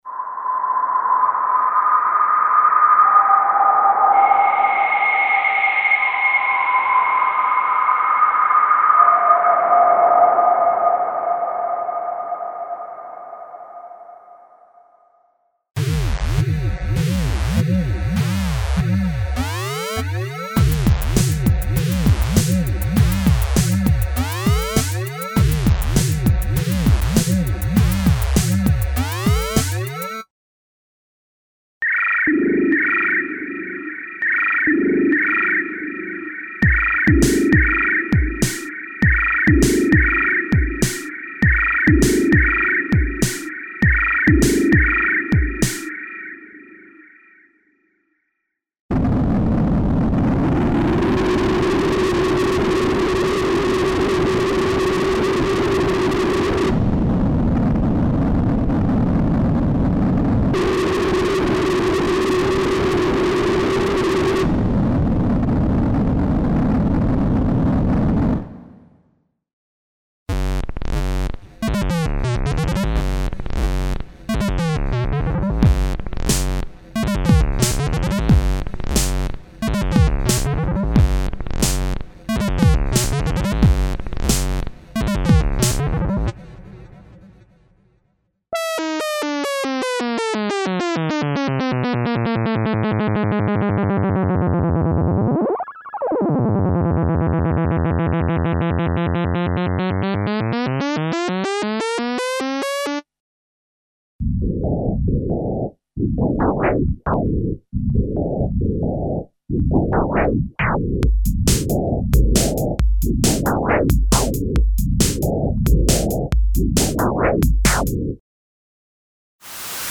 Emulations of vintage analog synthesizers (filtered and specially modulated noises and analog effects).
Info: All original K:Works sound programs use internal Kurzweil K2500 ROM samples exclusively, there are no external samples used.
K-Works - Analog Volume 3 - LE (Kurzweil K2xxx).mp3